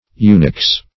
/yoo'niks/, n.